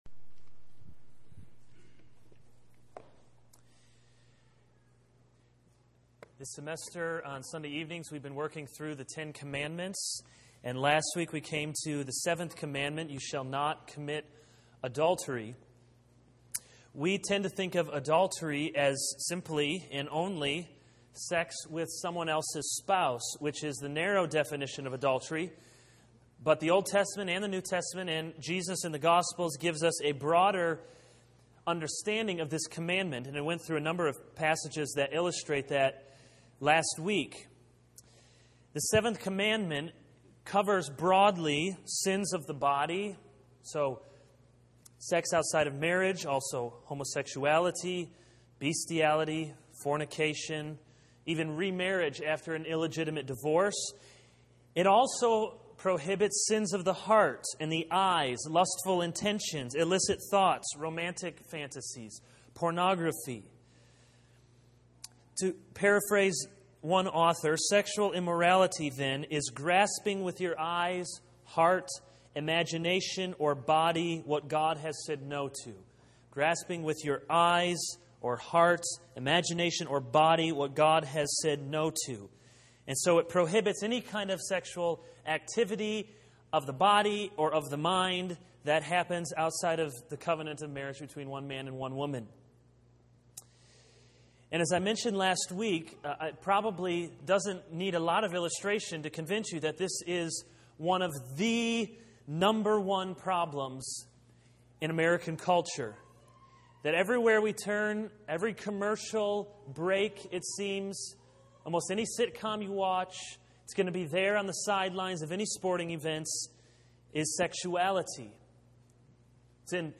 This is a sermon on Exodus 20:1-17 - Do not commit adultery.